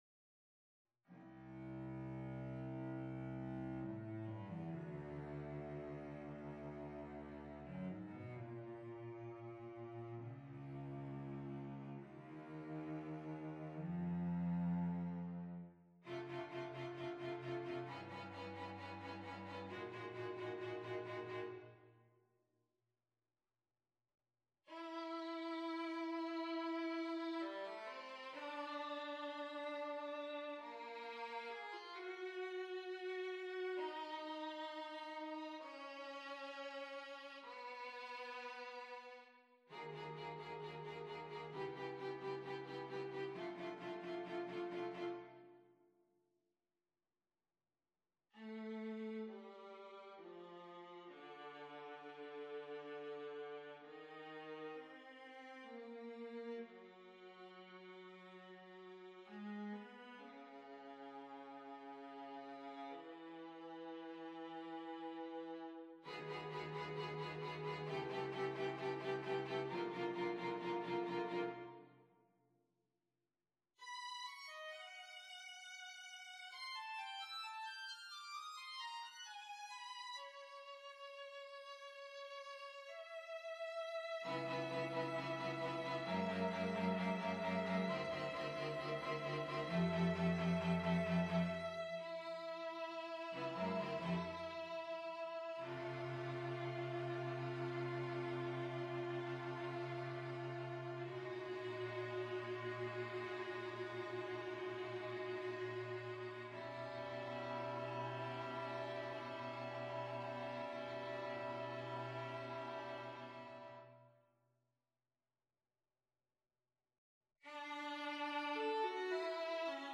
Grave. 2.
Allegretto. 3.
Largo. 5.
Gravissimo.